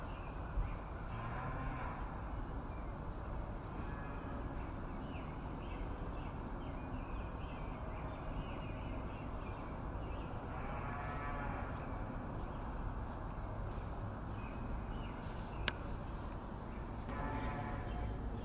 牲畜 " Moo
描述：在山区，只有老牛
标签： 奶牛 MOO
声道立体声